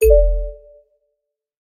menu-direct-click.ogg